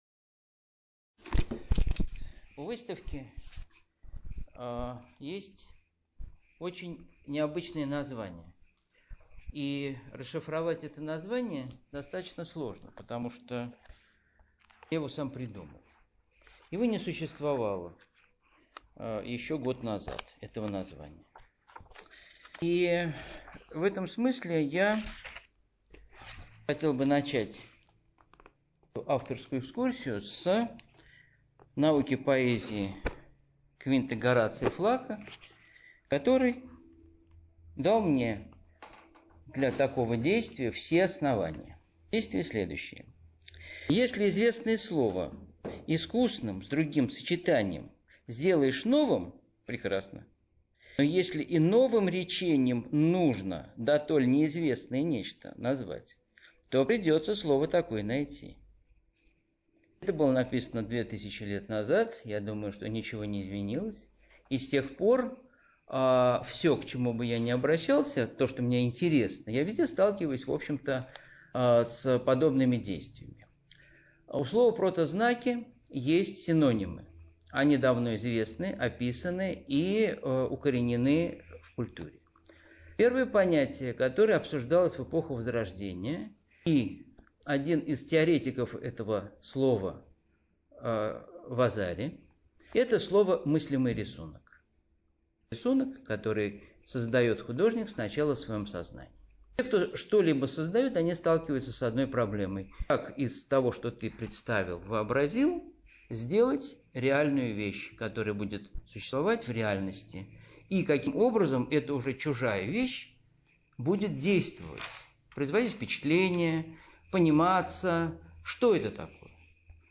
Запись авторской экскурсии
excursion.mp3